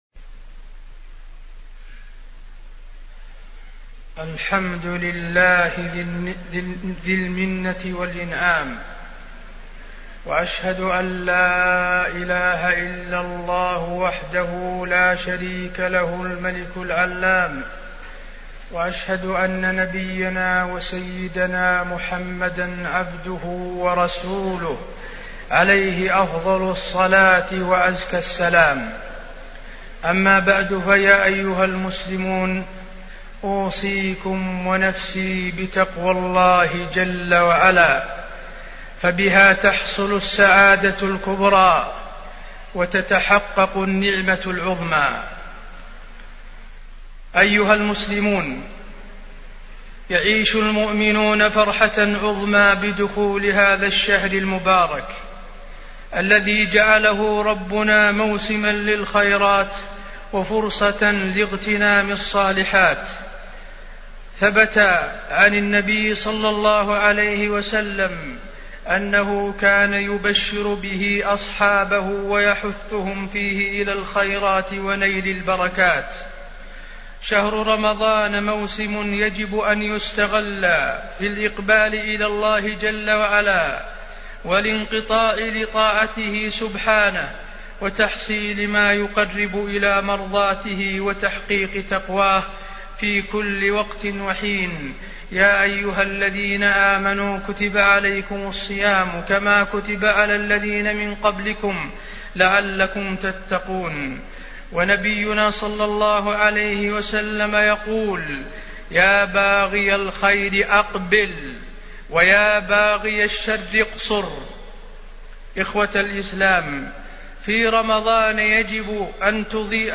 تاريخ النشر ٢ رمضان ١٤٢٨ هـ المكان: المسجد النبوي الشيخ: فضيلة الشيخ د. حسين بن عبدالعزيز آل الشيخ فضيلة الشيخ د. حسين بن عبدالعزيز آل الشيخ الصوم جنة